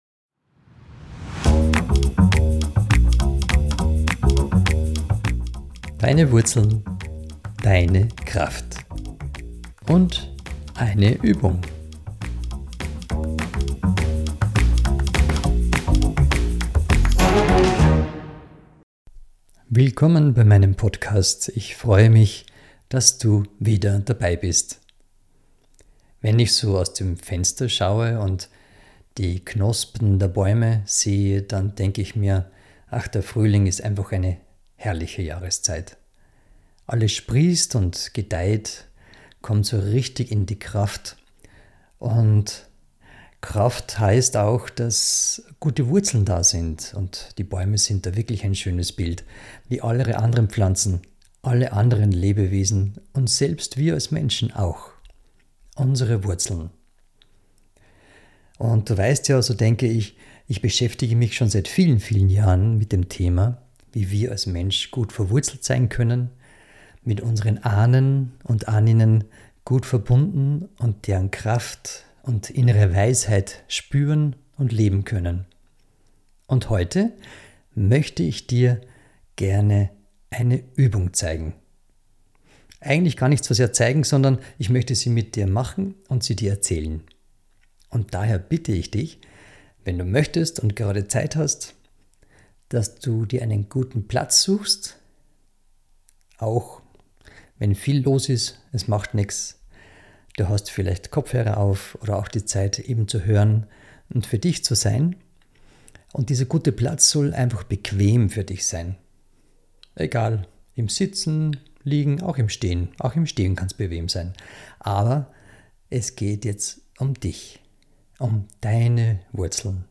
In dieser Folge zeige ich dir eine Übung, eine Meditation, die dich mit den Wurzeln, deiner tiefen Kraftquelle verbindet. Eine Quelle, die du schon immer in dir getragen hast.